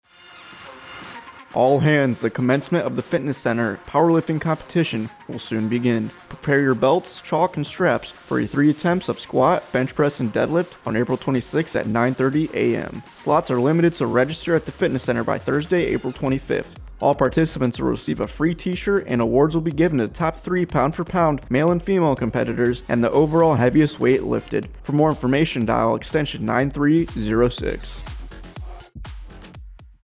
AFN Bahrain Radio Spot - Powerlifitng Competition
Thirty-second spot highlighting the MWR Powerlifitng Competition in Manama, Bahrain to be aired on AFN Bahrain’s radio show.